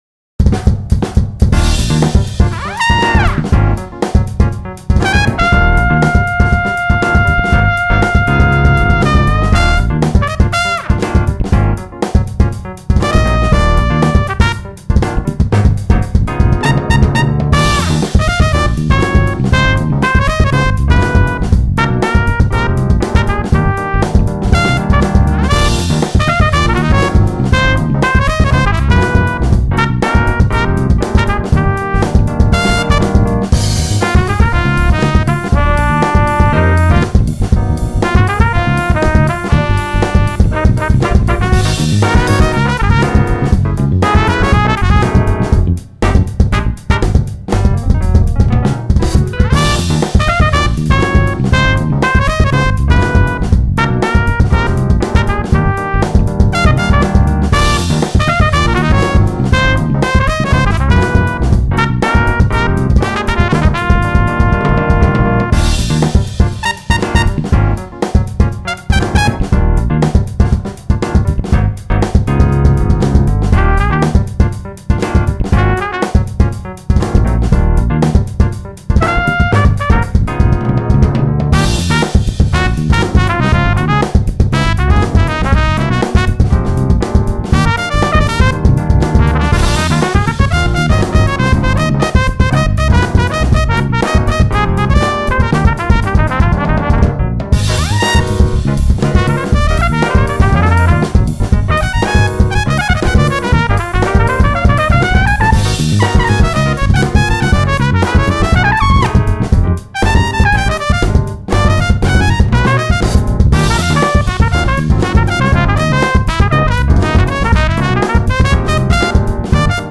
Some of these were recorded well over a decade ago on earlier versions so are far lower sound quality than is standard today.
Kick-Ass Brass! is the next best thing to a real horn section available for Mac or PC in Audio Unit, VST, AAX and Standalone versions.
New demo by All About Audio, all rights All About Audio Productions